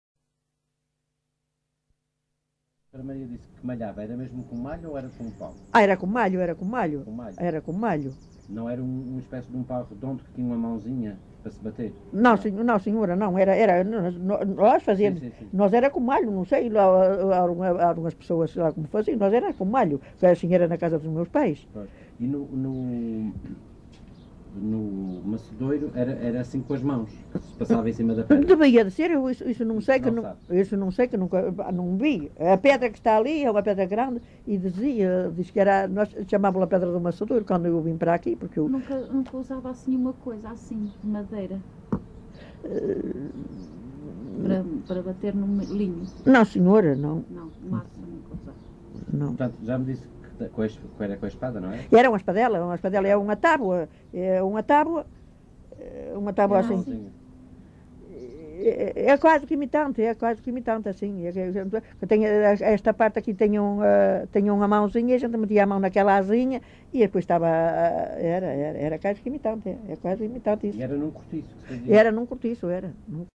LocalidadeArcos de Valdevez (Arcos de Valdevez, Viana do Castelo)